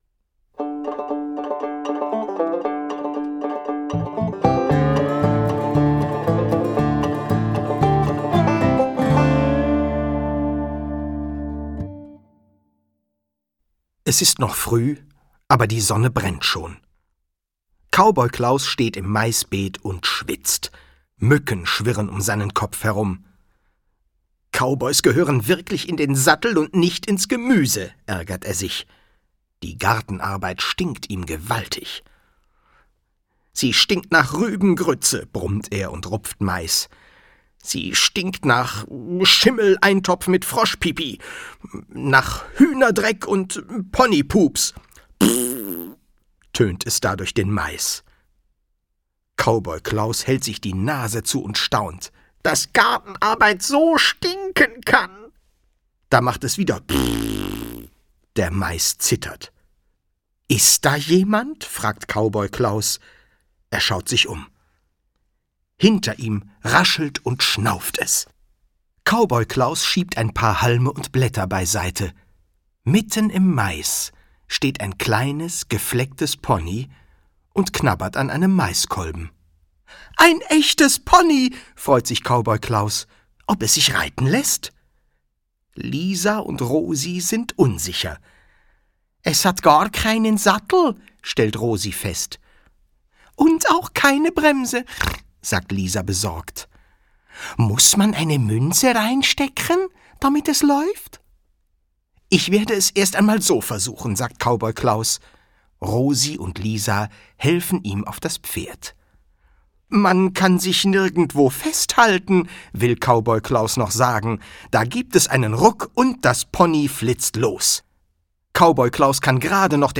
Durch seine Stimmvielfalt macht er aus seinen Lesungen kleine Hörspiele.
Schlagworte Abenteuergeschichten • Country • fieser Fränk • Hörbuch; Lesung für Kinder/Jugendliche • Hühner • Kaktuswald • Kuh-Casting • pupsen • Wilder Westen